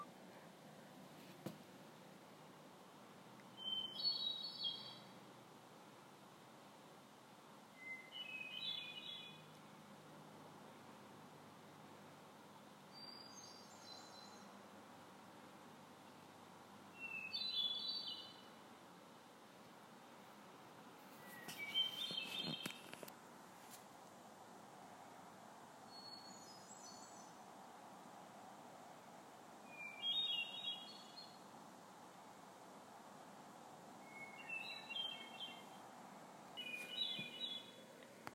The most poetic of birds, the Hermit Thrush, singing in the forest as the sun sets……
Hermit-Thrush-2018.m4a